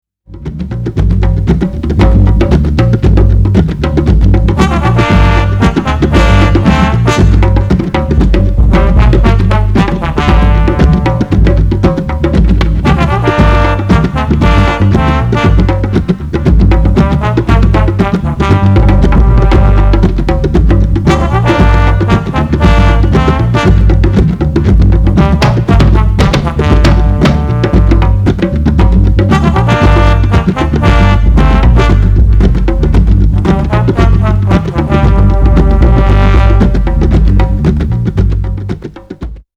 恐らく70年後半録音（新録かも。ちょい不明。）。
ライト・オブ・サバに通じる、ナイヤビンギ、アフロ、ファンク
等々のクロスオーバー・グルーヴィ・ナムバー！！
トライバル＆パーカッシブなナイヤビンギ、
地鳴りのような太く重いベース、
迫力ホーン、思わず腰が動く動く、